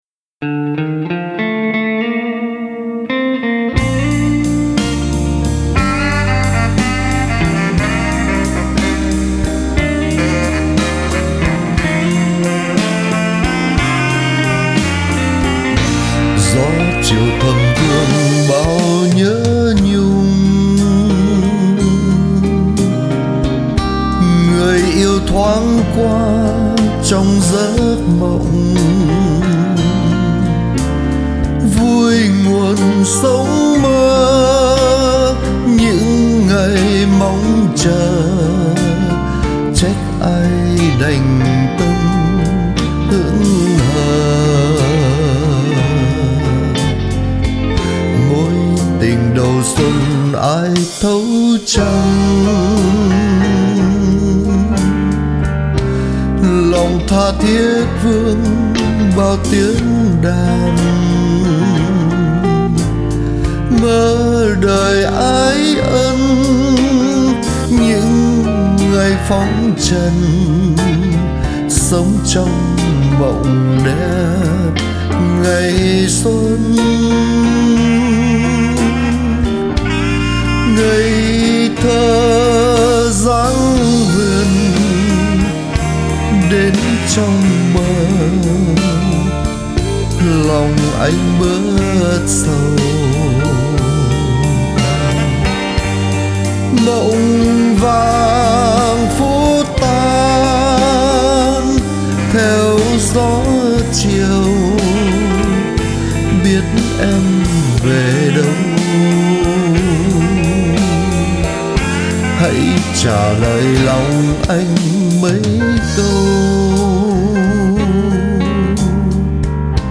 tiếng guitar ngọt lịm ở đoạn giữa và đoạn chót